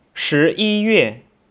(Click on any Chinese character to hear it pronounced.
shiyiyue.wav